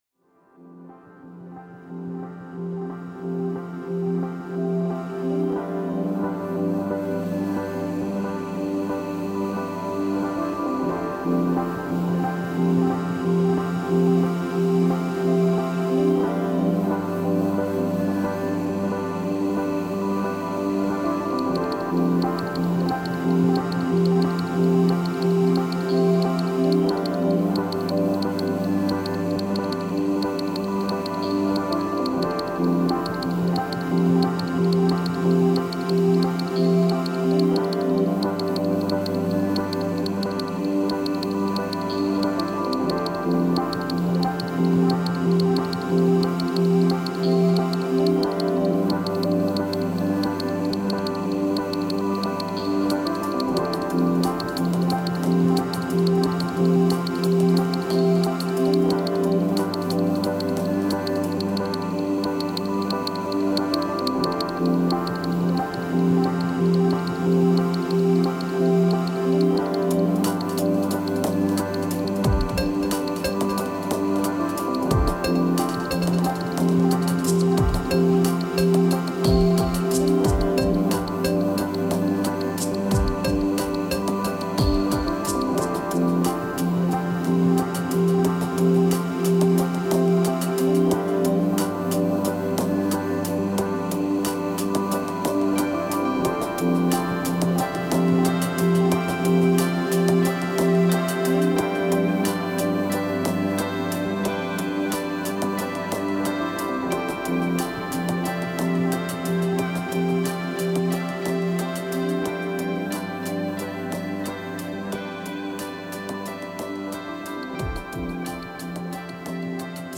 upbeat electronic music
The fast-paced tempo and driving-rhythms